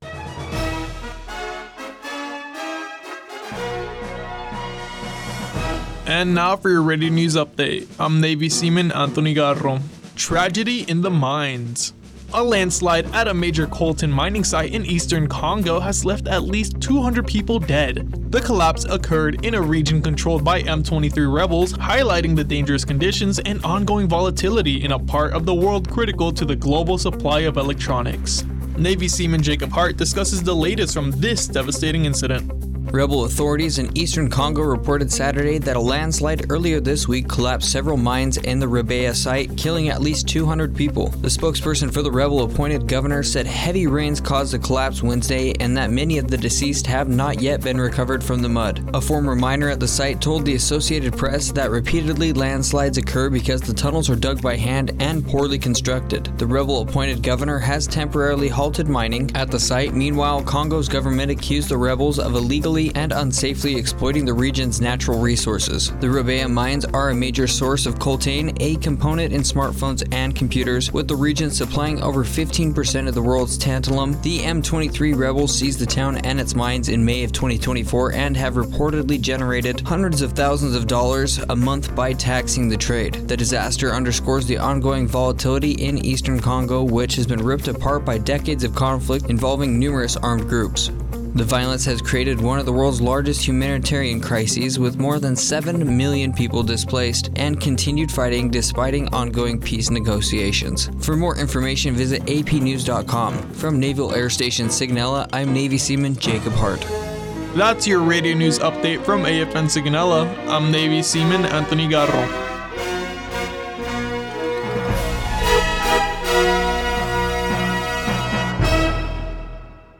AFNNASSIGMining AccidentEastern CongoRadio News